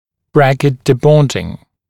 [‘brækɪt dɪ’bɔndɪŋ][‘брэкит ди’бондин]отклеивание брекета (-ов), снятие брекета (-ов)